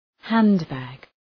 Προφορά
{‘hænd,bæg}